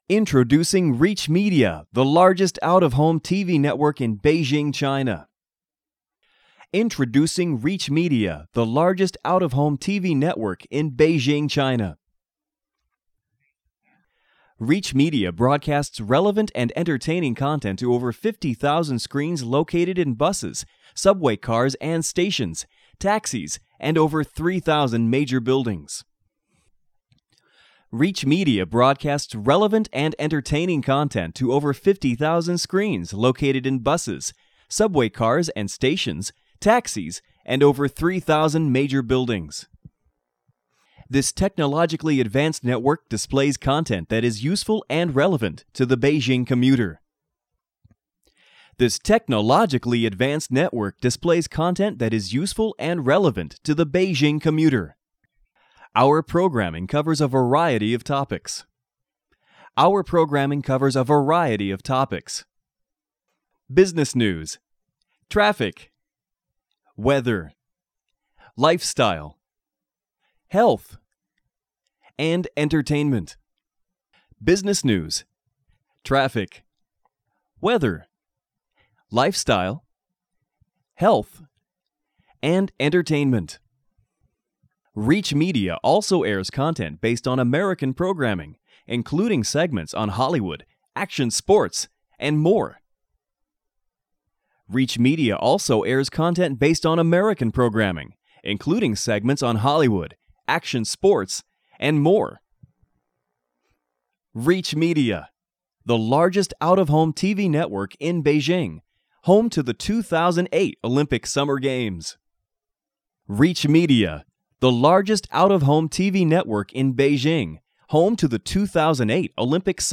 Male
Adult (30-50), Older Sound (50+)
Pleasant baritone, natural & conversational - Aggressive over-the-top "Truck Rally" delivery - Character voices - Radio announcer/personality - Audiobook Narration
Corporate